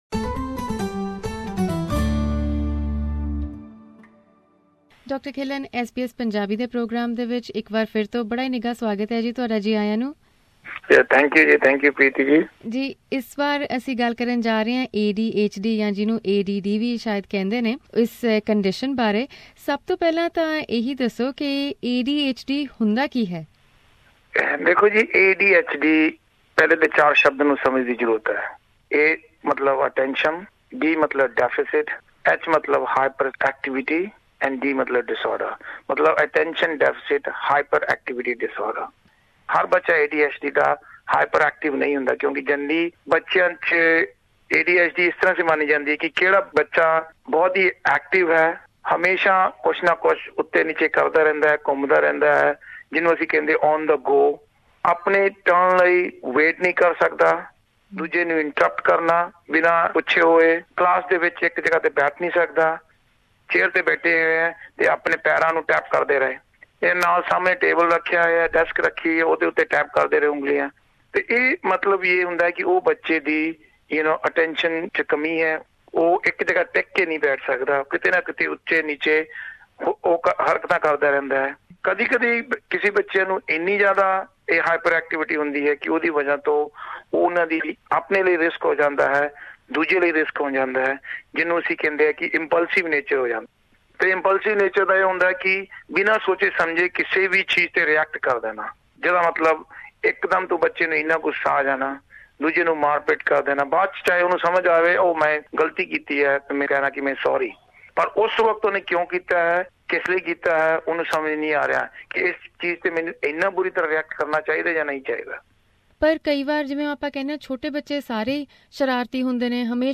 SBS Punjabi talks to well-known paediatrician from Melbourne